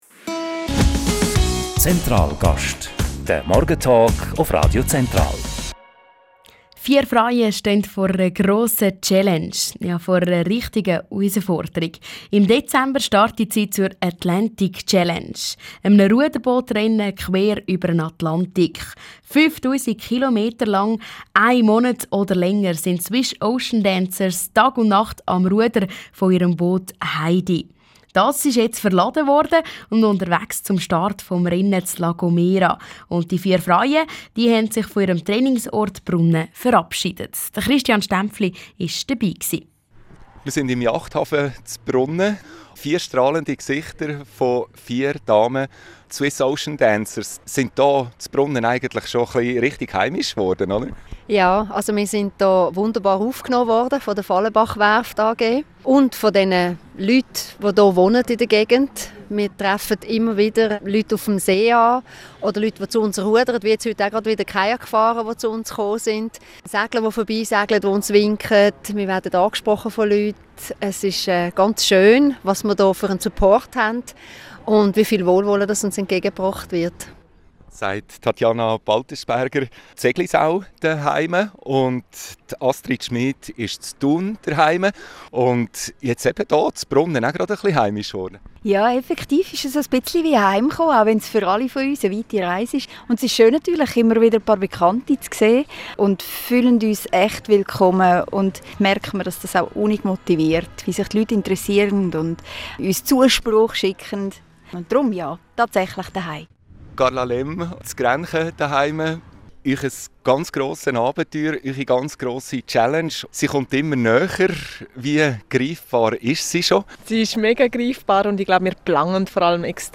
Radio Central Interview mit den SwissOceanDancers